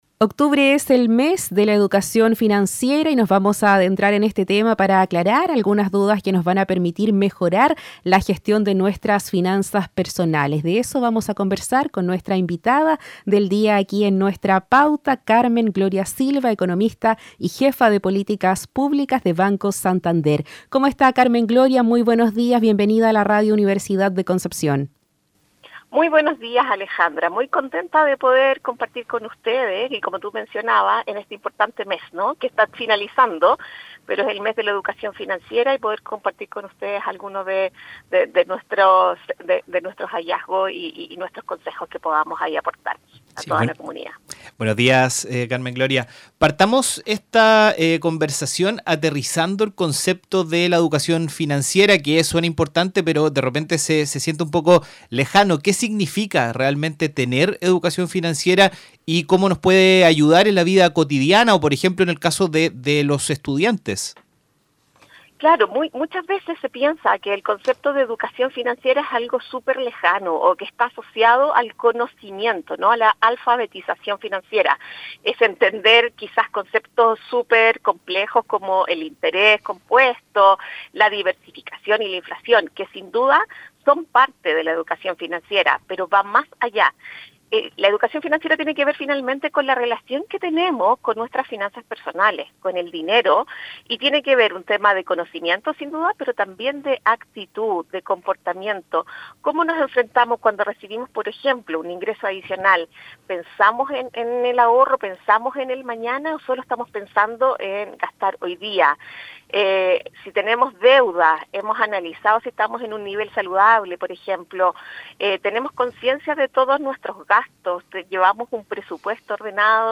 Entrevista-Santander.mp3